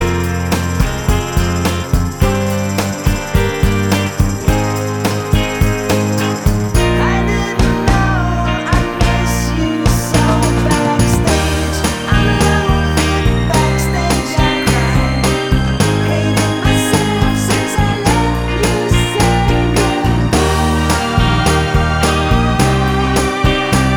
Two Semitones Down Pop (1960s) 2:57 Buy £1.50